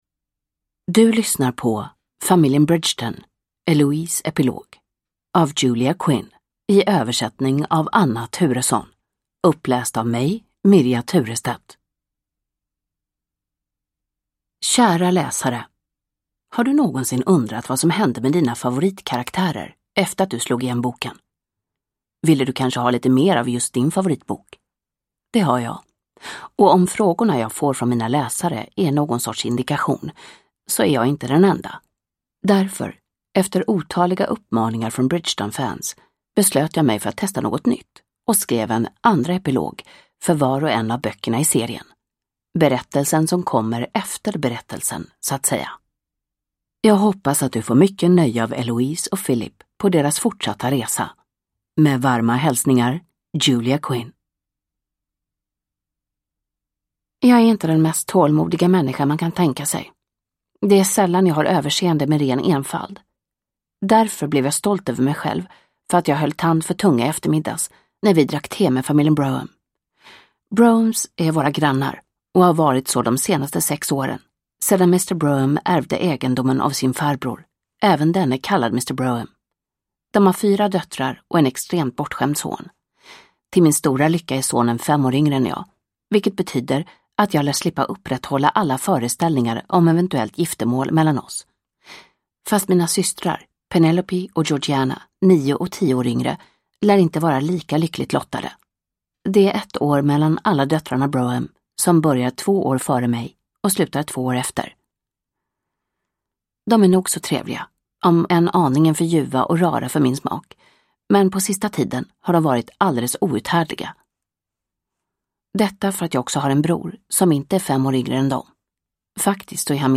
Eloises epilog – Ljudbok – Laddas ner